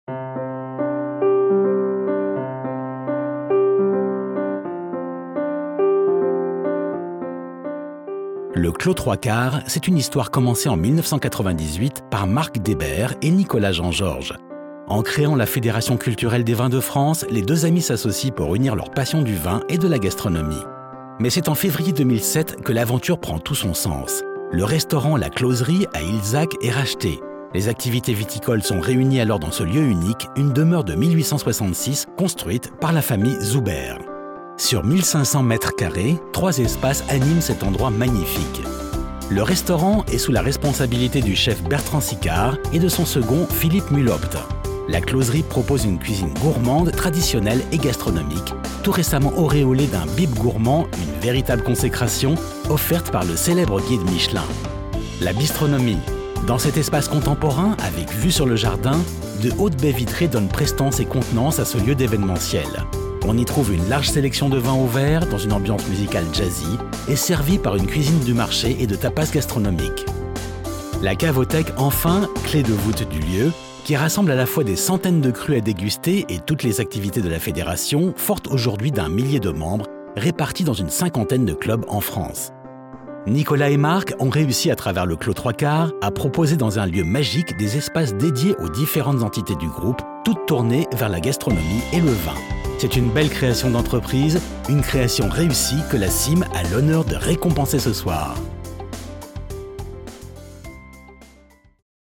Comédien Voix Off, Français et Anglais
Sprechprobe: Sonstiges (Muttersprache):
My voice is deep and my vocal range goes from low to medium, I work both in English and in French.